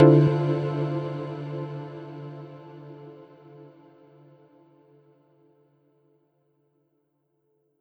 pause-continue-click.wav